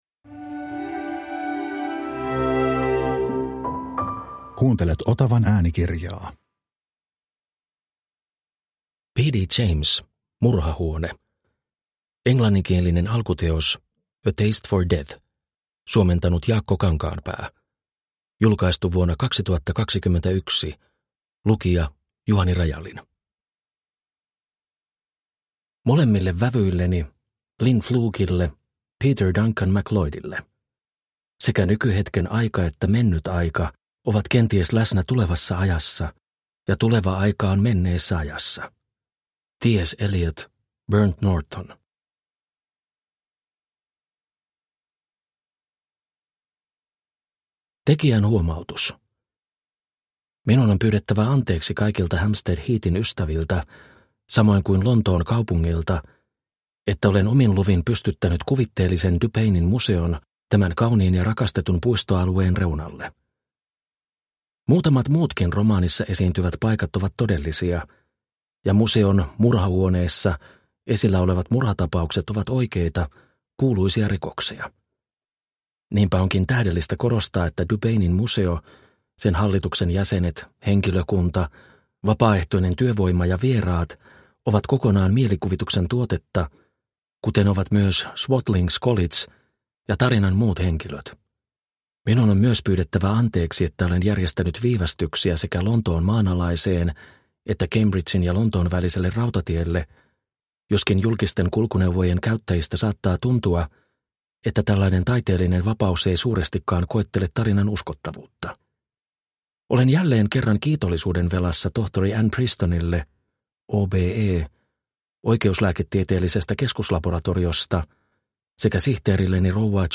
Murhahuone – Ljudbok – Laddas ner